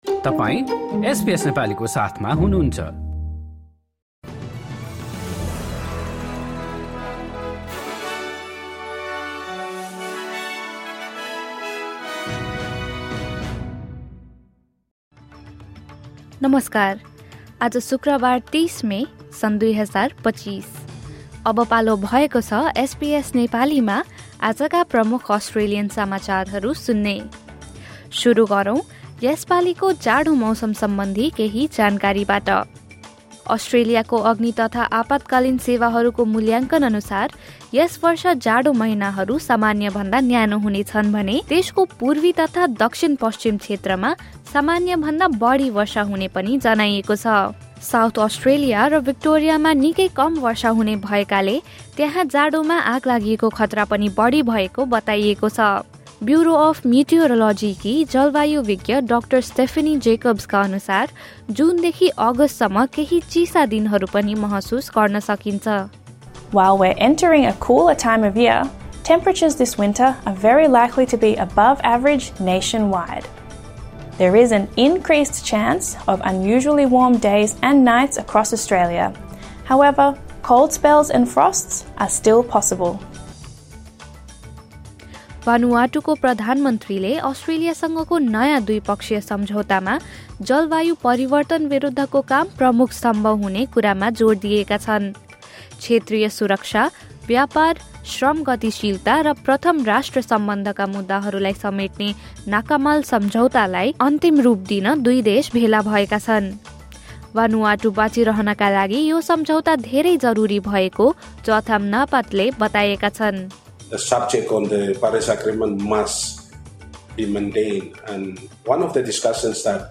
एसबीएस नेपाली प्रमुख अस्ट्रेलियन समाचार: शुक्रवार, ३० मे २०२५